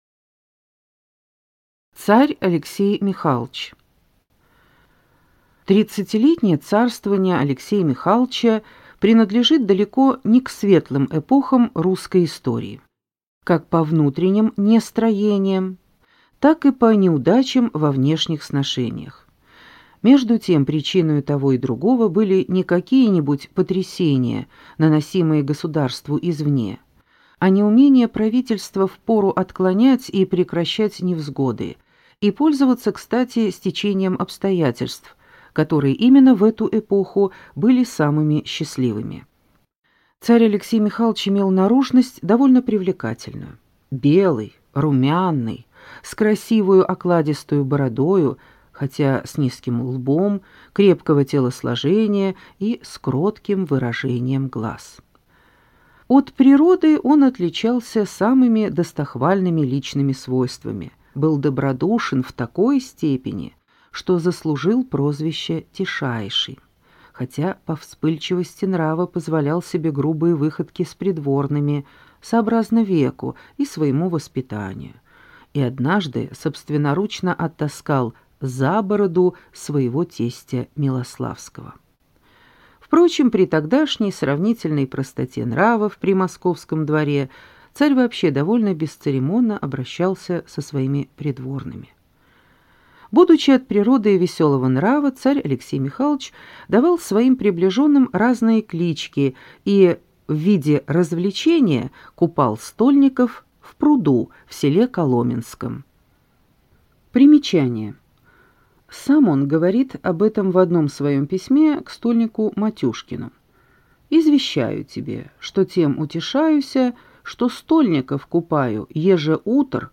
Аудиокнига Русская история.Том 8. Господство дома Романовых до вступления на престол Екатерины II | Библиотека аудиокниг